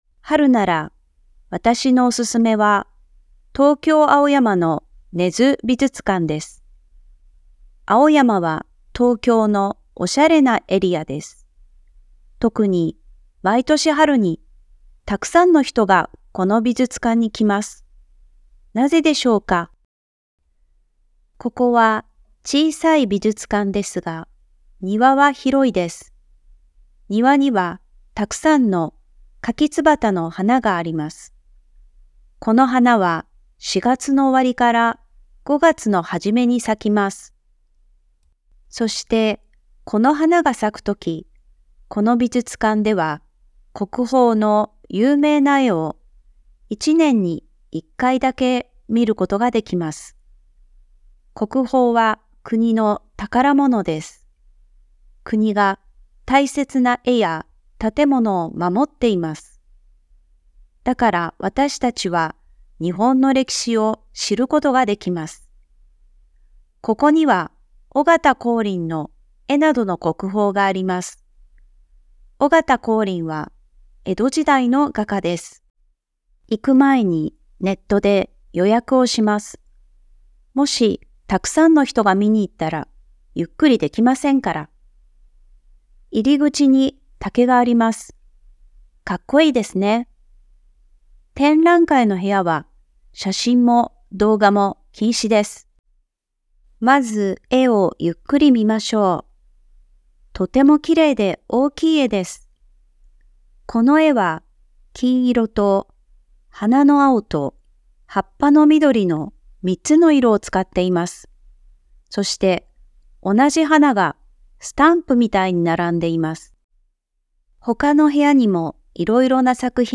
Lecture en japonais : Trésors nationaux Tokyo PDF, MP3, exercices